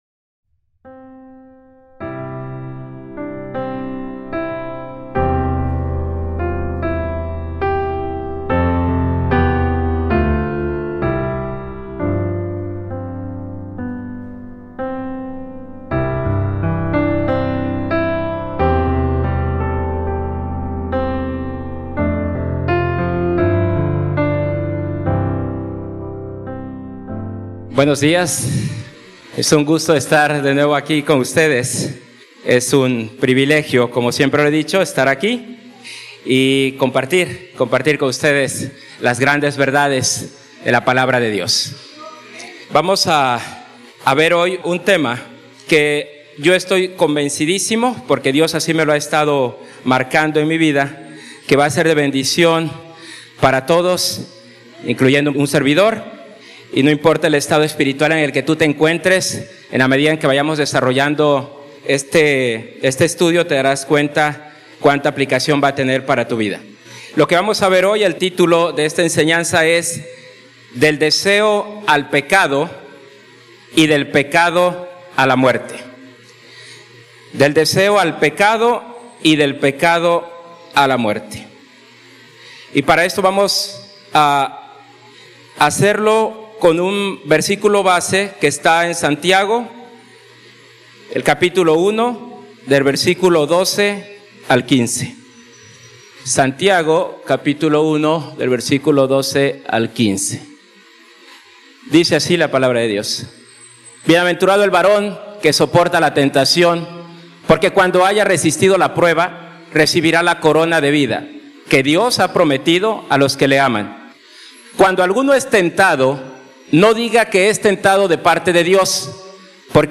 Predicación textual basada en el pasaje de la epístola de Santiago 1:12–15